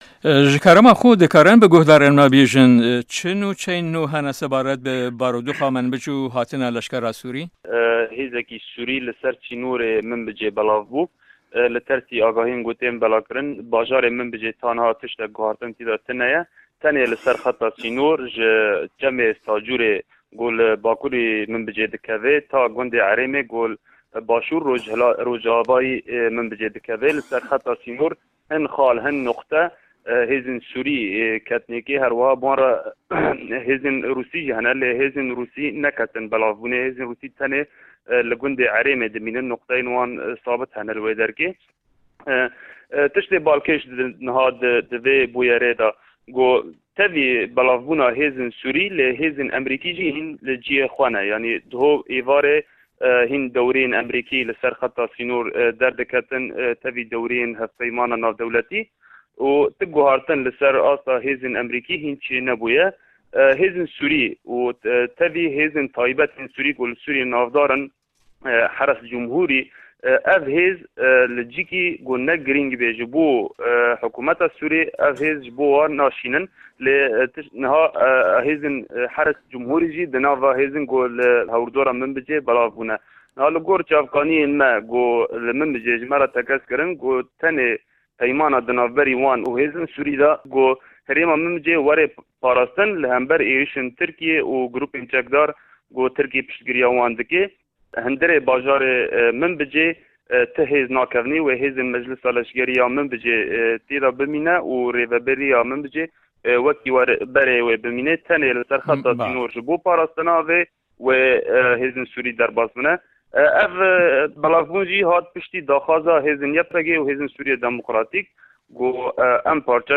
ڕاپۆرت په‌یامنێری ده‌نگی ئه‌مه‌ریکا